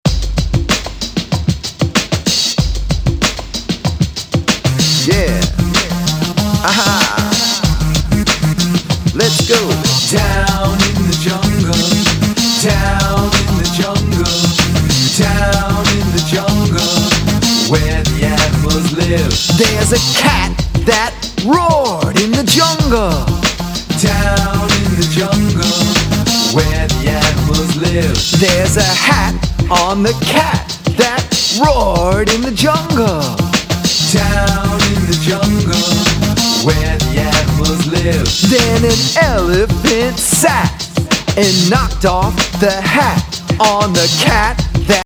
Hip-Hop and Pop Beats to Help Your Children Read